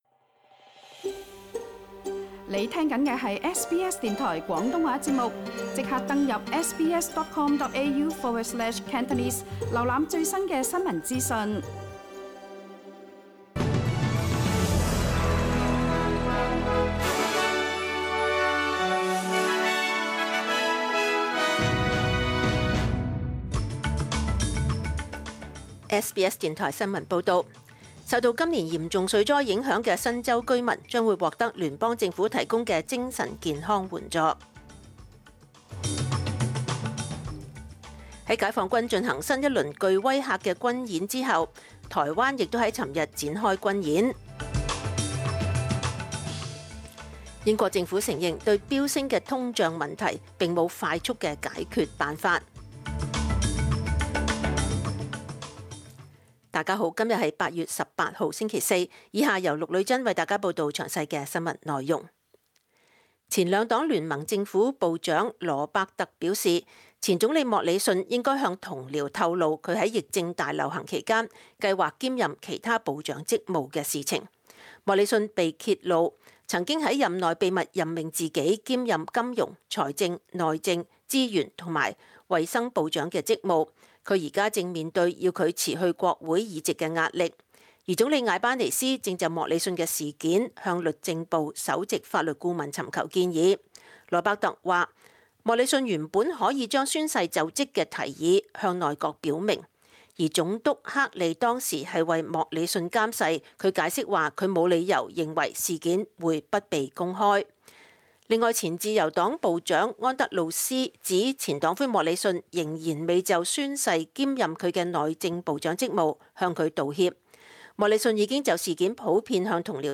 SBS 廣東話節目中文新聞 Source: SBS / SBS Cantonese